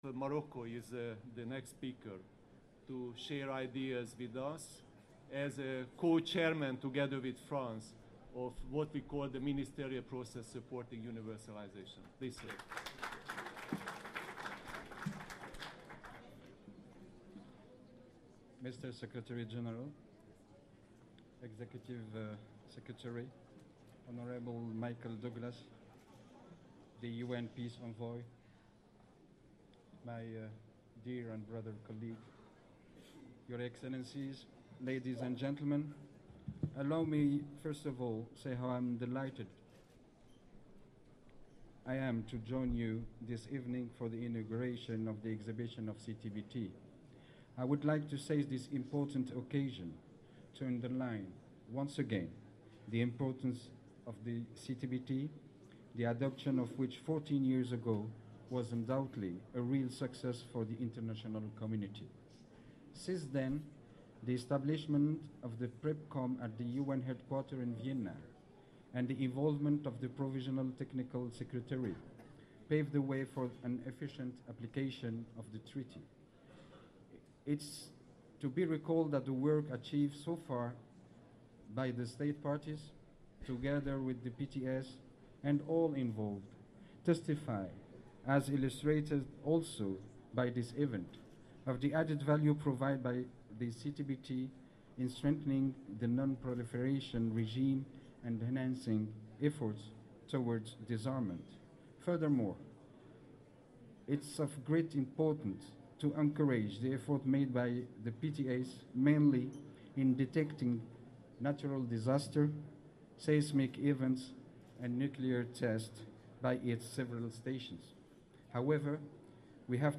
Morocco Foreign Minister Taib Fassi Fihri at CTBTO reception